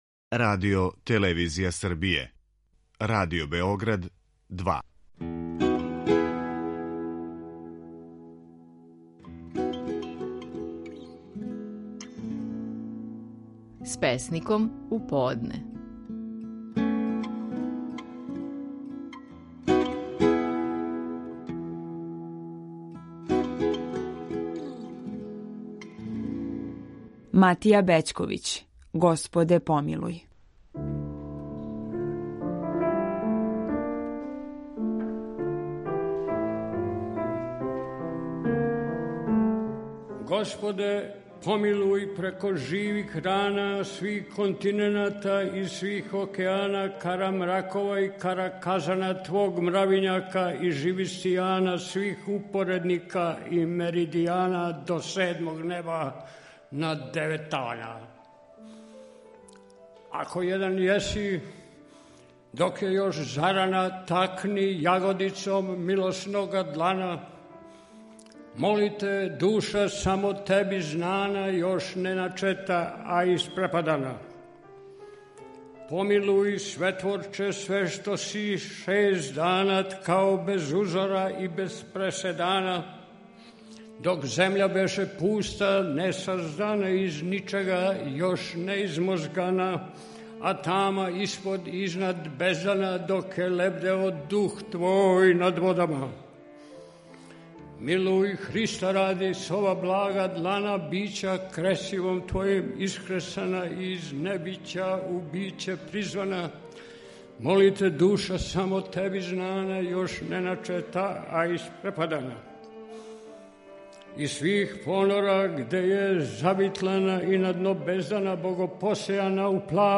Стихови наших најпознатијих песника, у интерпретацији аутора.
Матија Бећковић говори стихове песме: „Господе помилуј".